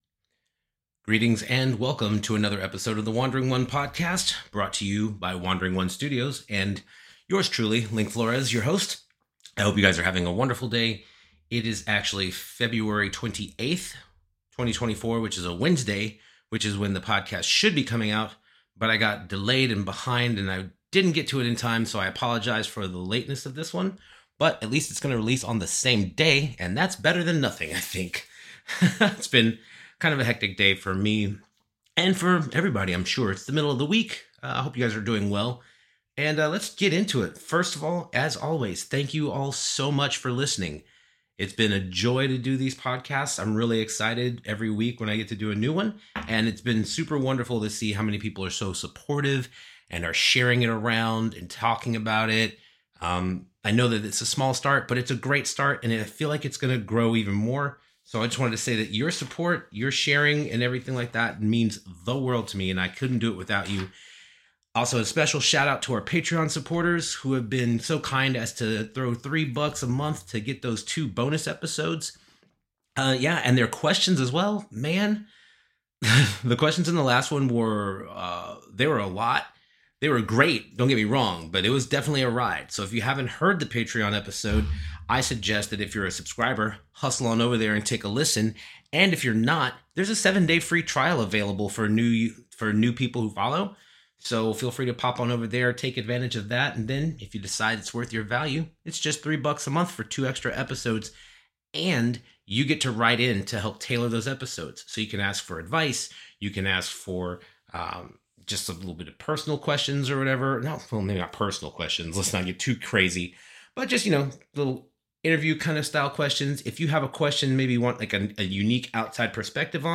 <<< WARNING: May contain adult language and thematic content. Listener discretion is advised. >>>